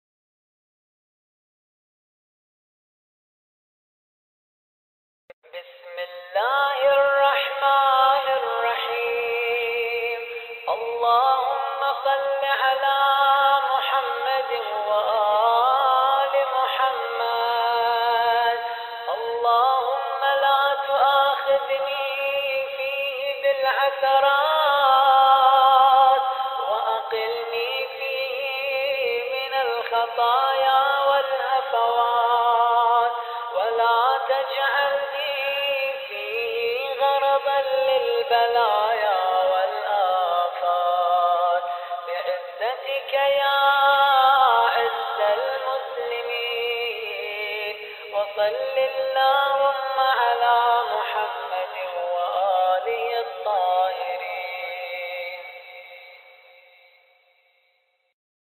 دعای روز چهاردهم ماه مبارک رمضان